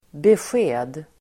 Uttal: [besj'e:d]